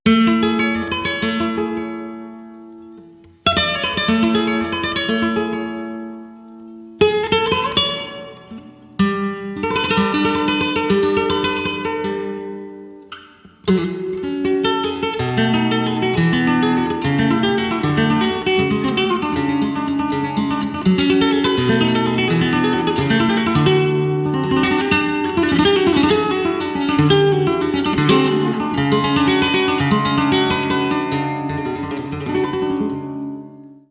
Tarantas / 5 falsetas